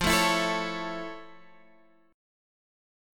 F+M7 chord